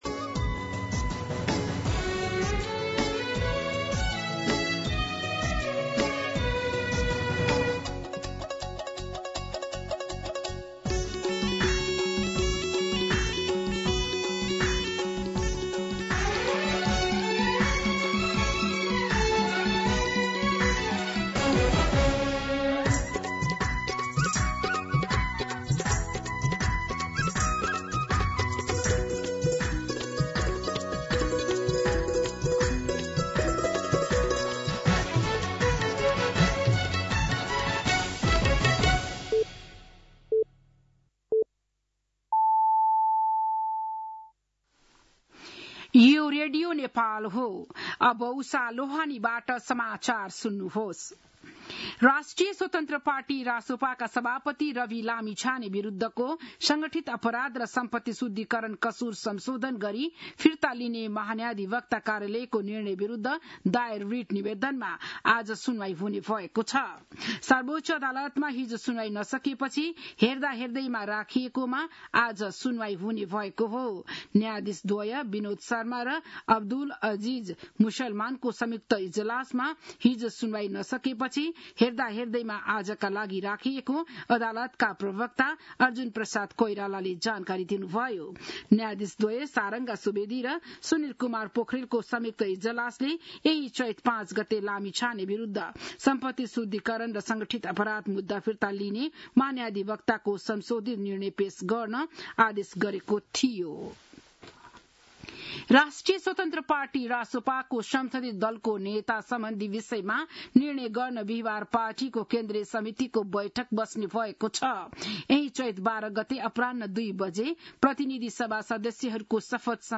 बिहान ११ बजेको नेपाली समाचार : १० चैत , २०८२